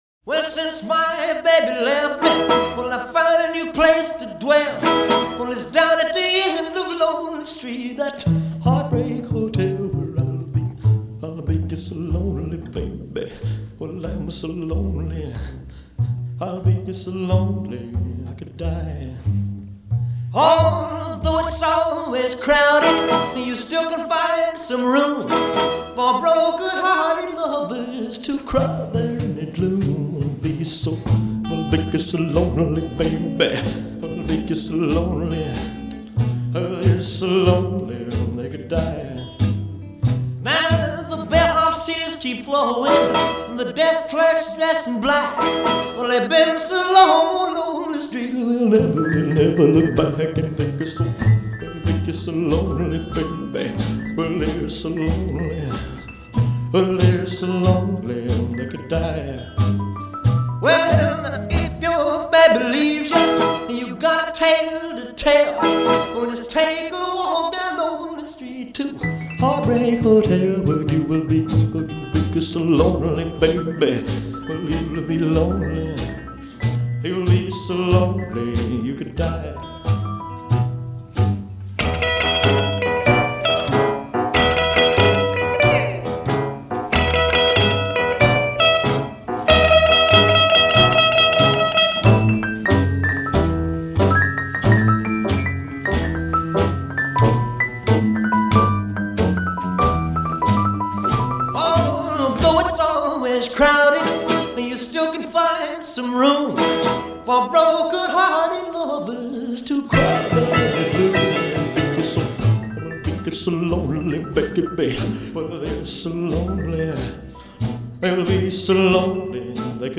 Rock and Roll